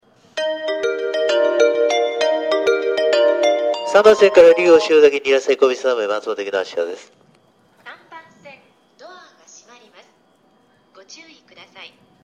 発車メロディー途中切りです。始発電車なので余韻以上は高確率で期待できます。